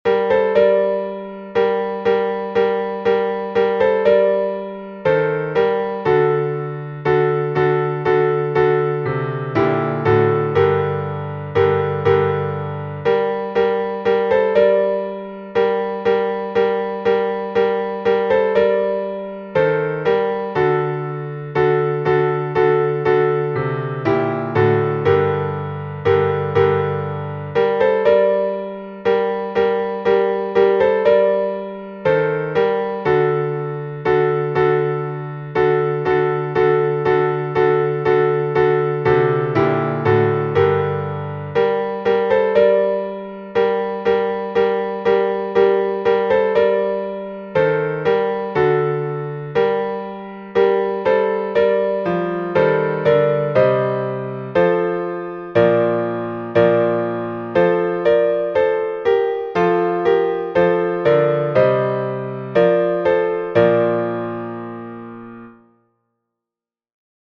Напев Троице-Сергиевой Лавры, глас 6